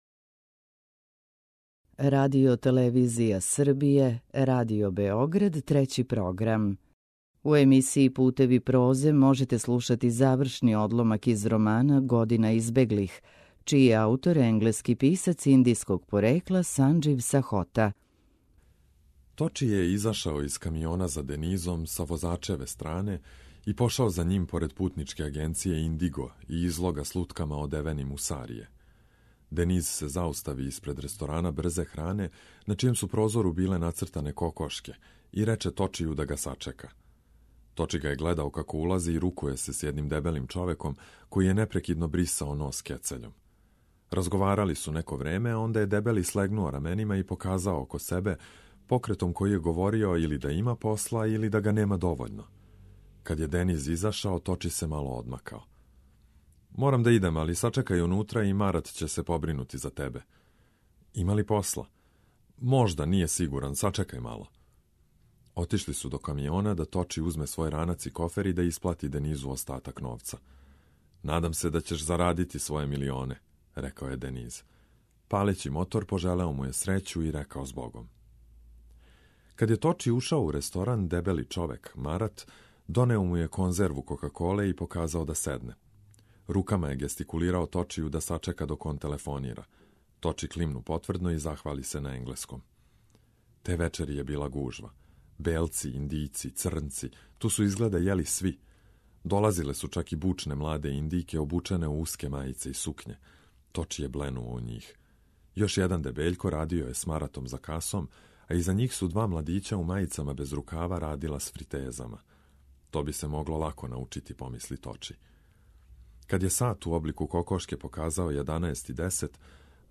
У циклусу Путеви прозе ове недеље можете слушати део романа „Година избеглих”, чији је аутор британски писац индијског порекла Санџив Сахота.